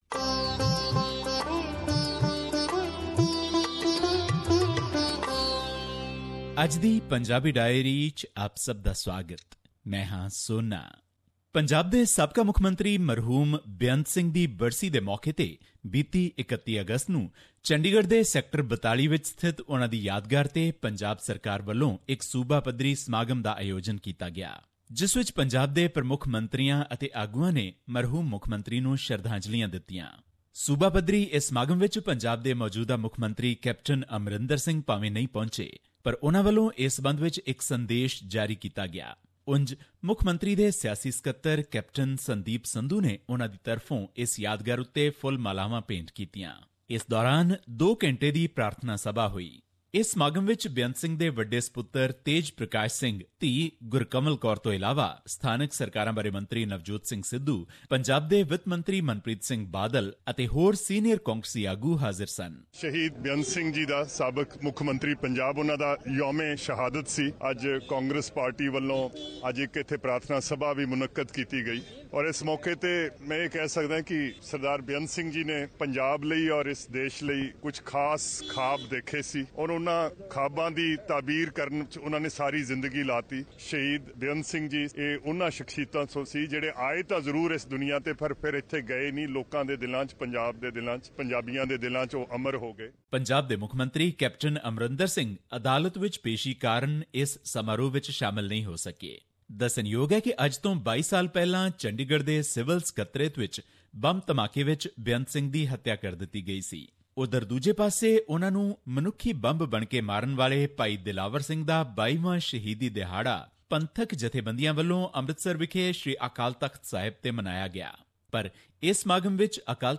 This report was presented on SBS Punjabi program on Sep 4, 2017, which touched upon issues of Punjabi and national significance in India. Here is the podcast in case you missed hearing it on the radio.